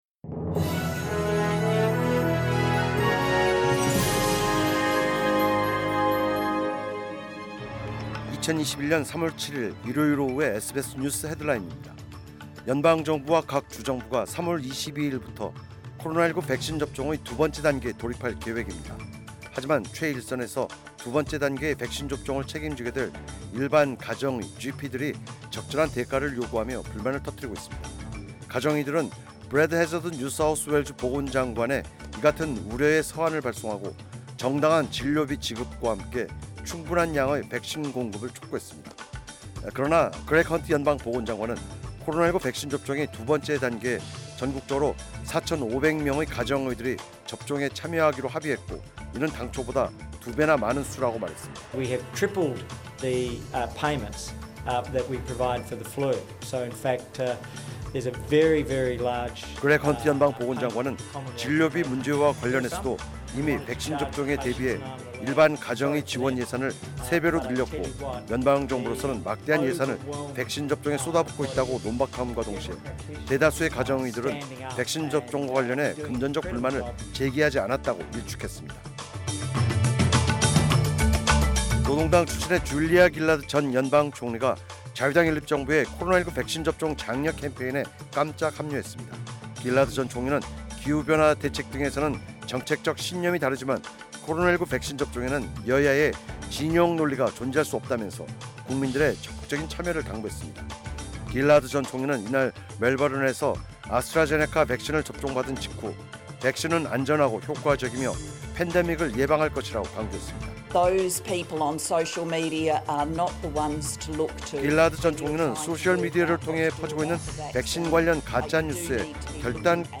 2021년 3월 7일 일요일 오후의 SBS 뉴스 헤드라인입니다.